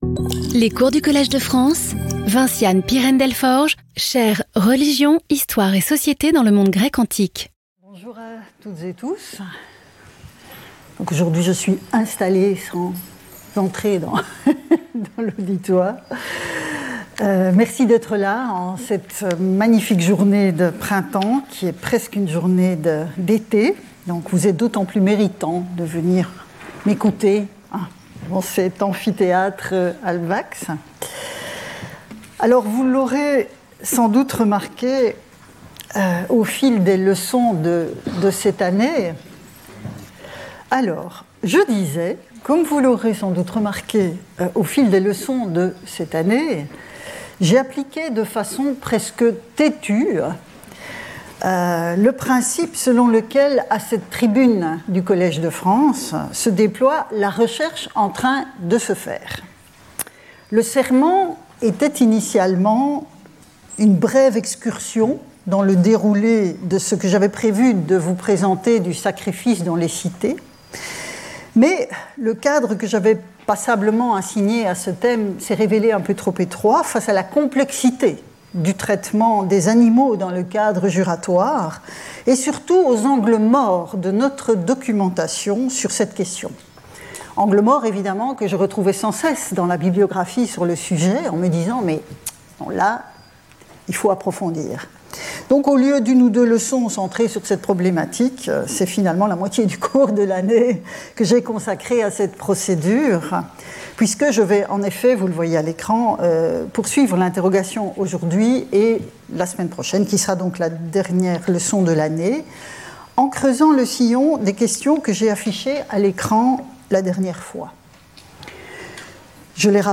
Professeure du Collège de France
Cours